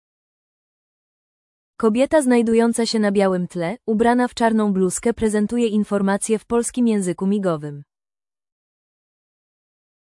Pliki do pobrania Centralne Biuro Antykorupcyjne tekst odczytywalny maszynowo (docx, 12.17 KB) 20.03.2025 10:23 Audiodeskrypcja materiału wideo (mp3, 78.8 KB) 16.06.2025 13:10
Audiodeskrypcjamaterialuwideo.mp3